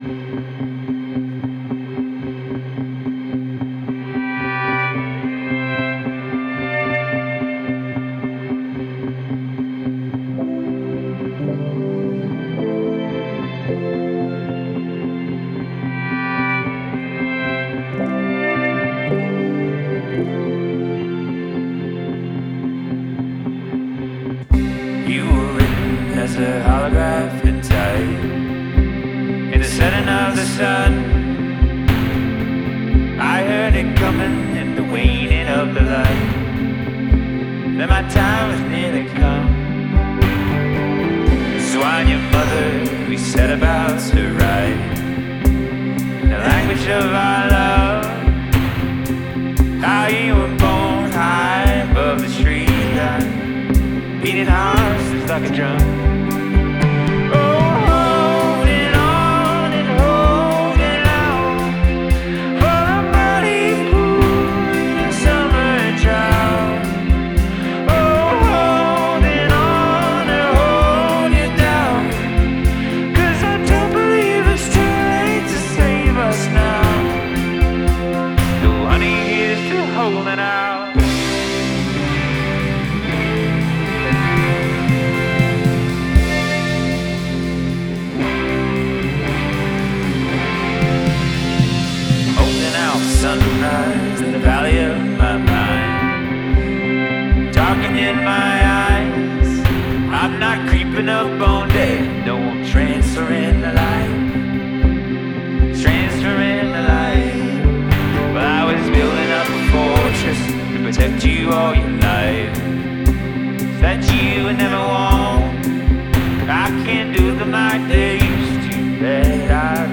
We believe in presenting artists exactly as they perform.